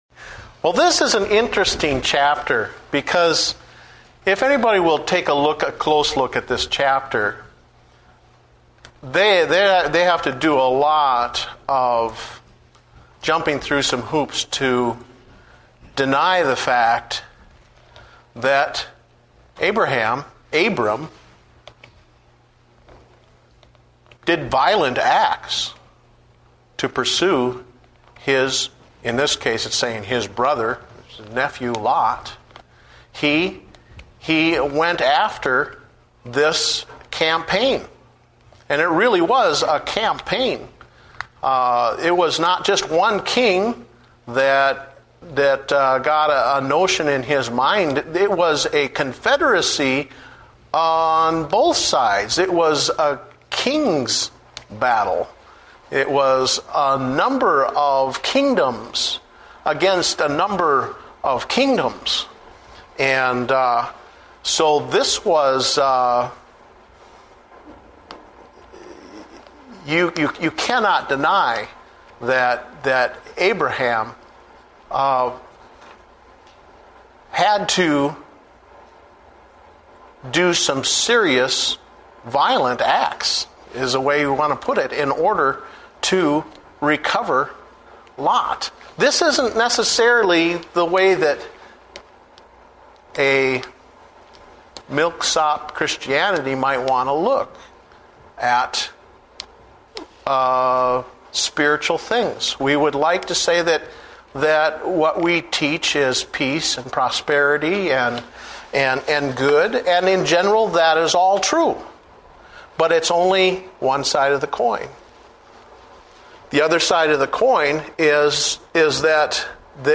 Date: February 15, 2009 (Adult Sunday School)